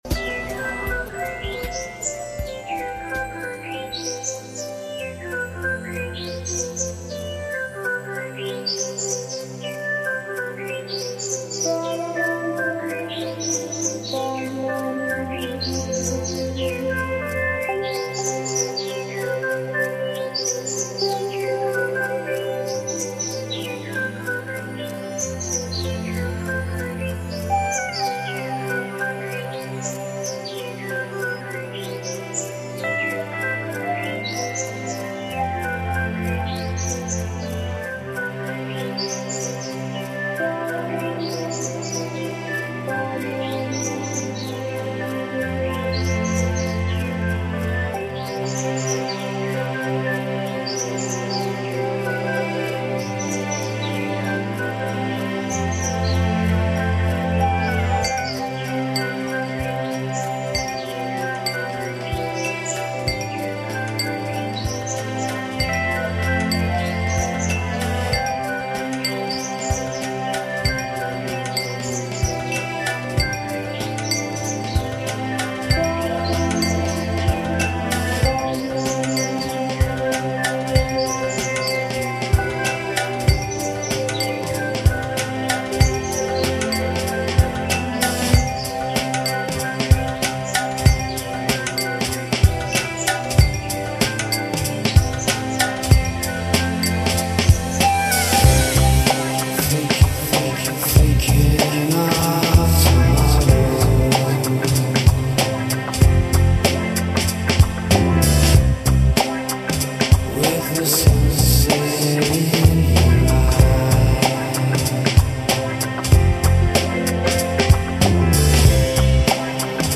Lo-Fi, ChillOut